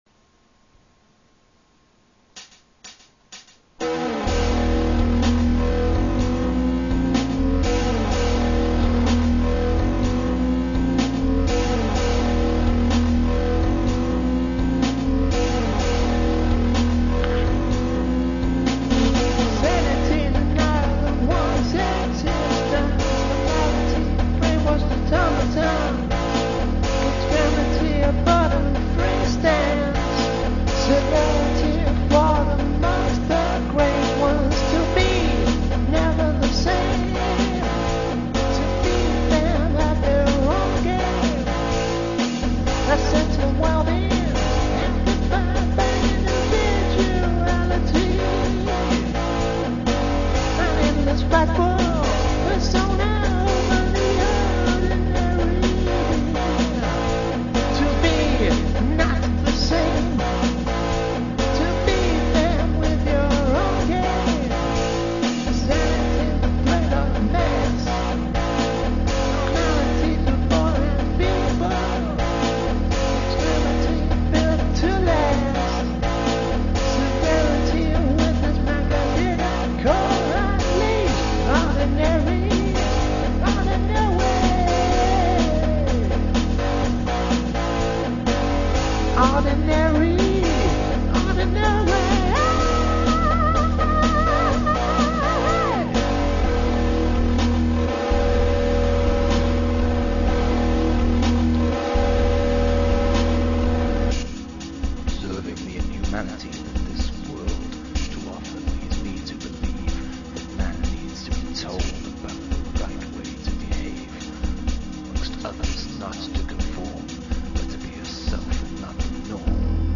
(A very early work in progress)